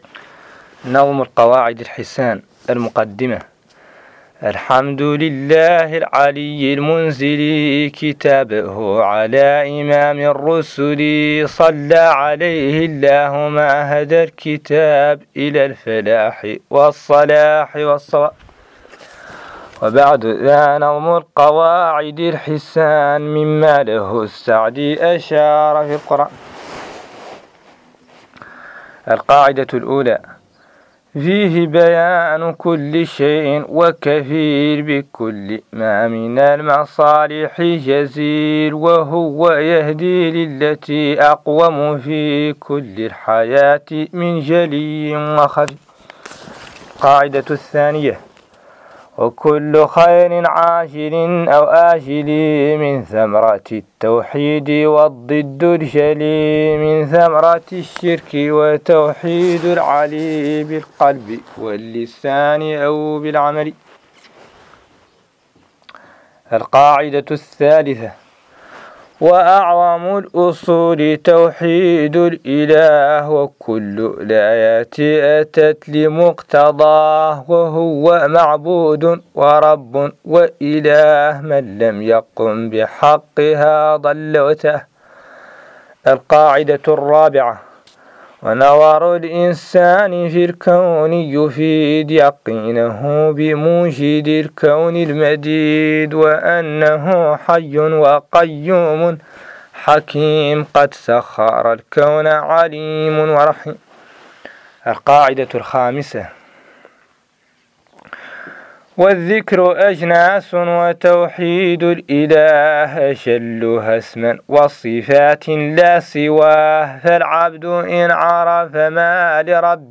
قراءة نظم القواعد الحسان